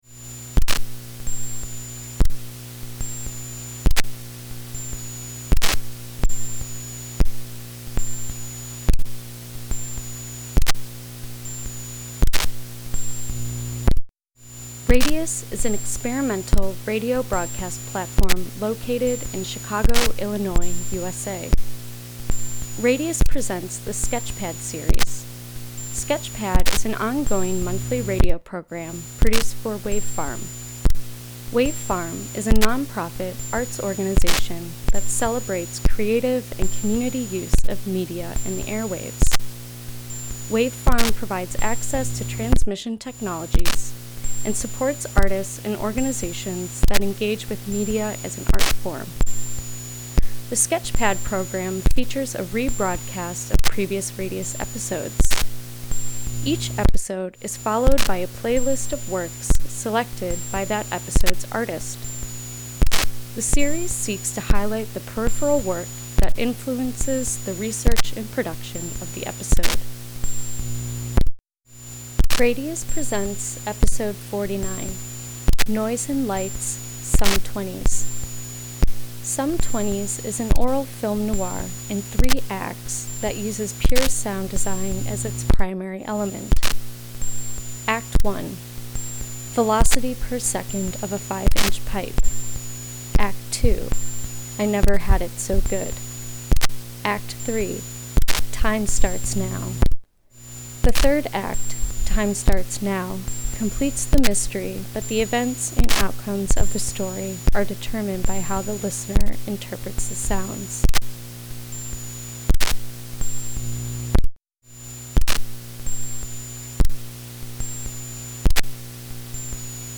"Sum Twenties" is an aural film noir in three acts that uses pure sound design as its primary element. Act I: Velocity Per Second of a 5 inch Pipe Act II: I Never Had It So Good Act III: Time Starts Now The third act, Time Starts Now, completes the mystery, but the events and outcomes of the story are determined by how the listener interprets the sounds.